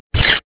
Index of /cstrike/sound/weapons